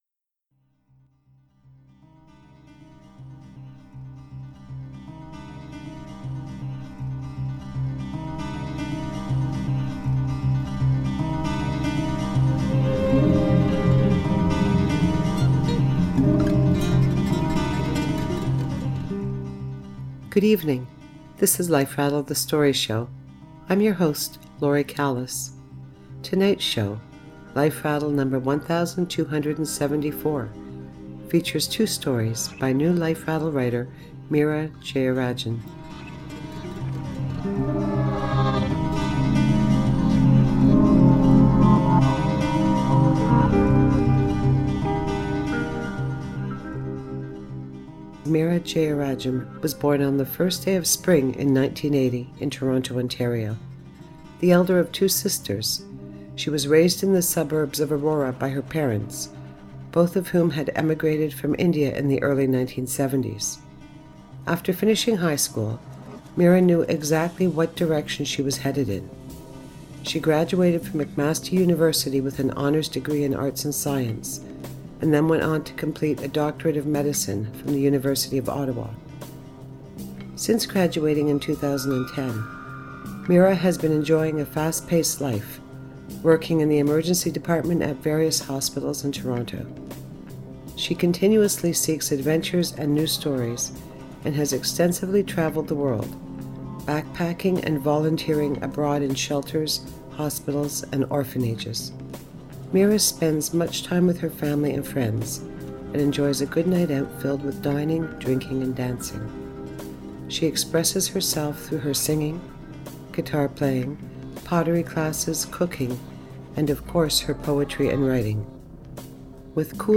Please note: tonight’s stories include intense situations.